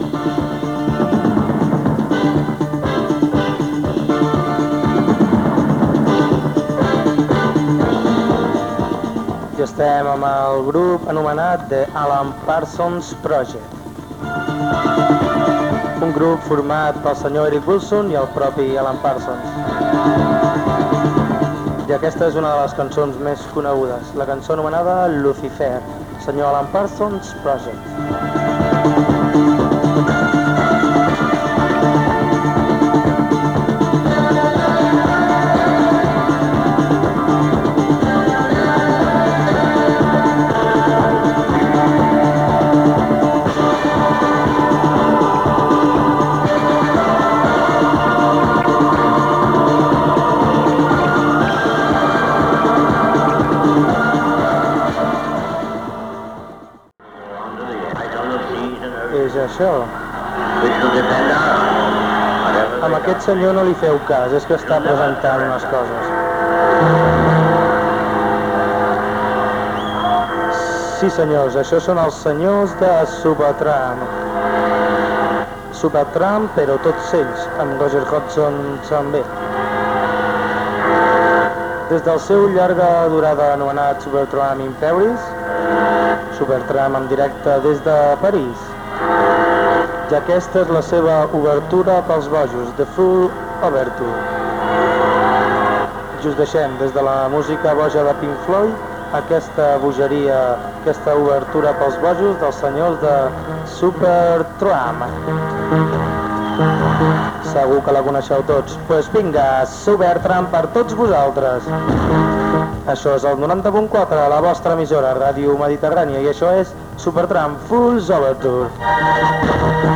Temes musicals i identificació de l'emissora.
Musical
FM